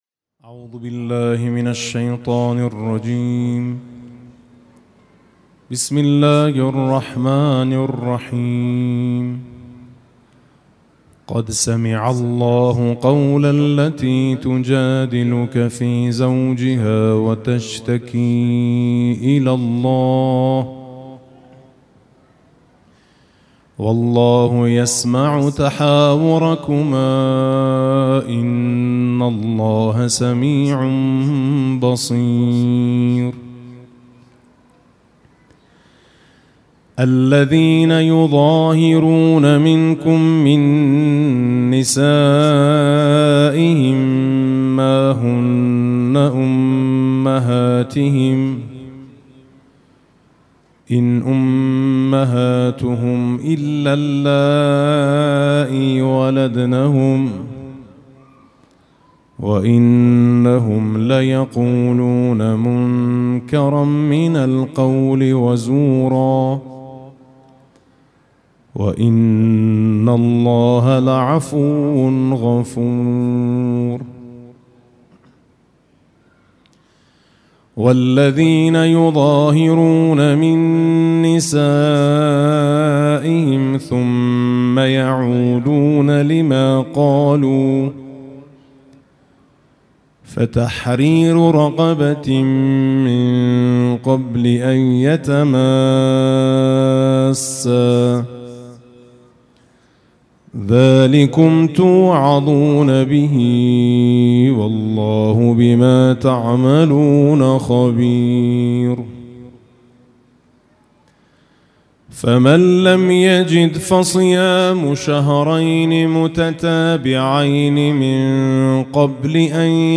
تلاوت سوره‌ی مبارکه «مجادله»
Motiee-QoranAl-Mujadila.mp3